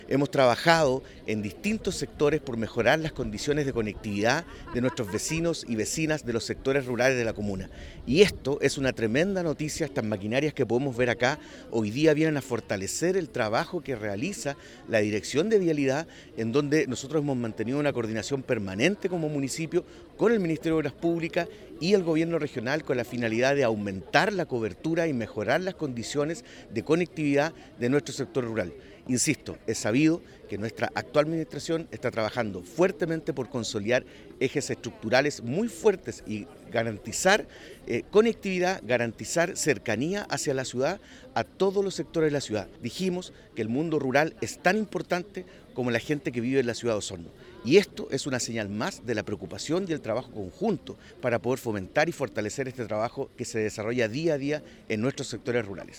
El Alcalde (S) de Osorno, Claudio Villanueva explicó que se ha trabajado en distintos sectores rurales para dar respuesta a las necesidades de conectividad de los vecinos, lo que se verá aumentado gracias a este convenio.